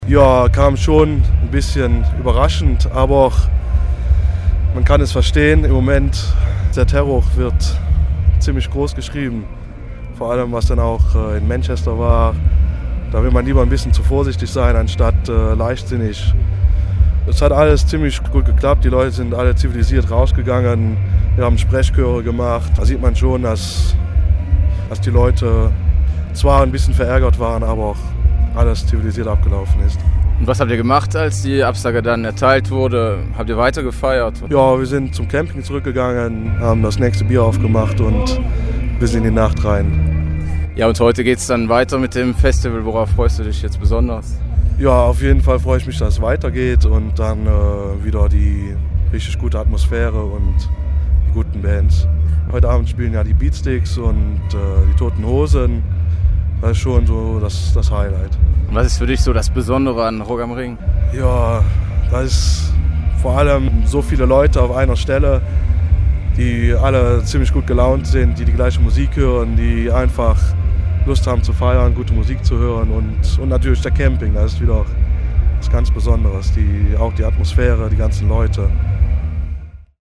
auf dem Camping